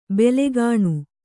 ♪ belegāṇu